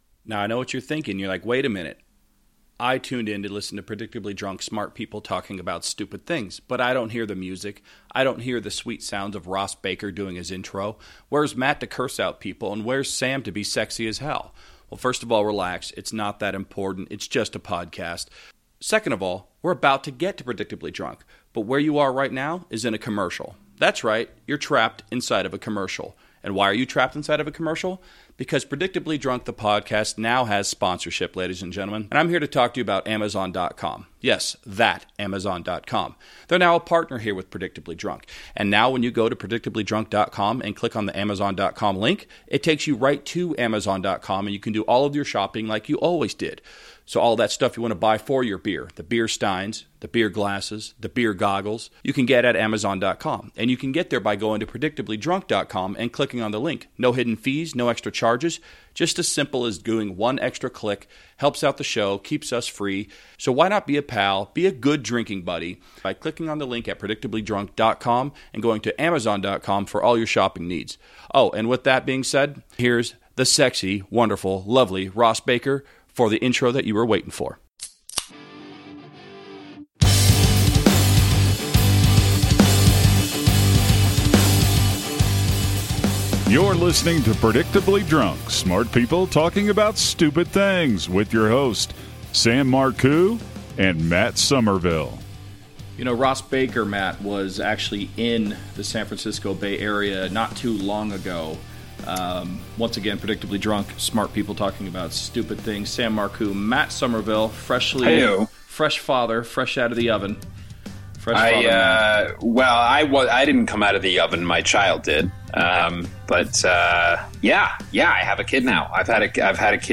at Cobb’s Comedy Club in San Francisco